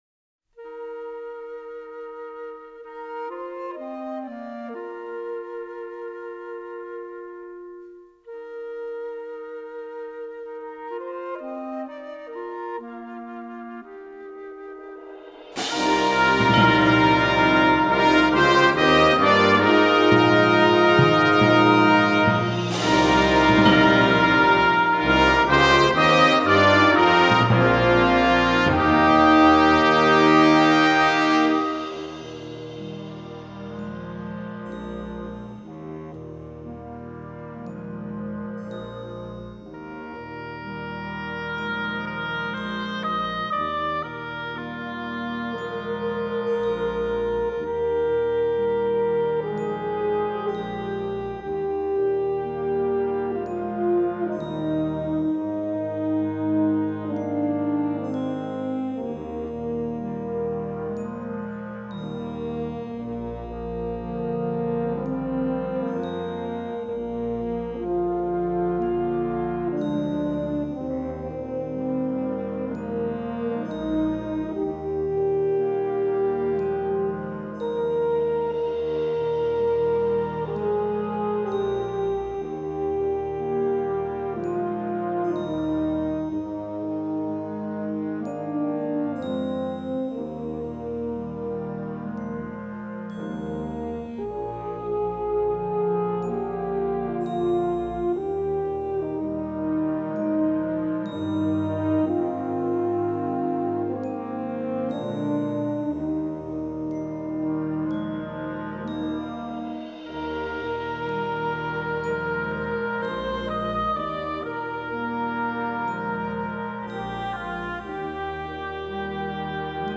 Instrumentation: concert band
instructional, children